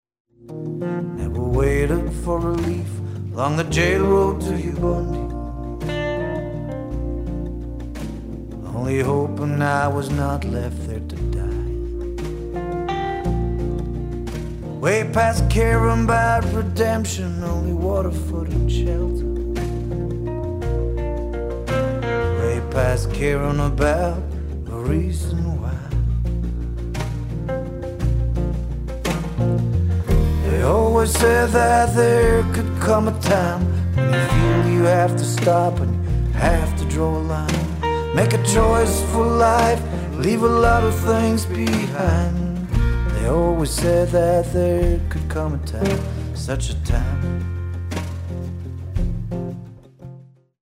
drums
bass